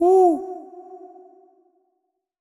owl_hoot_with_reverb_02.wav